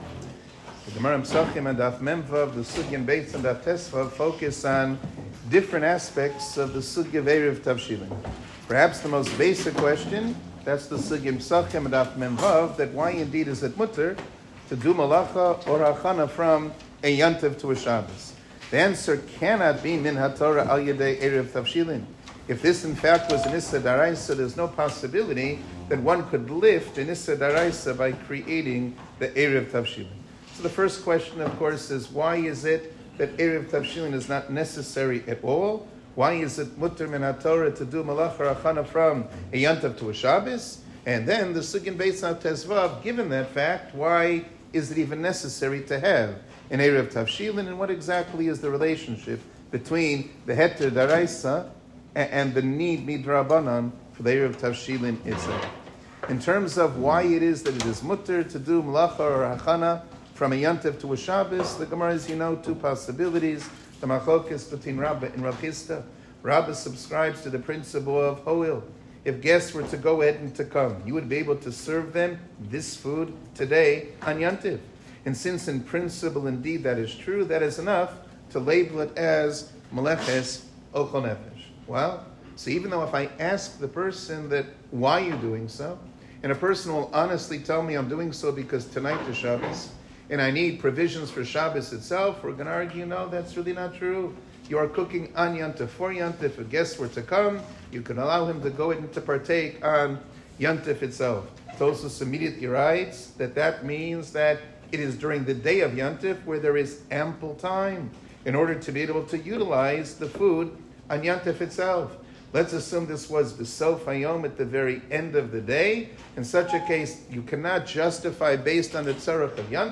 שיעור כללי - עירובי תבשילין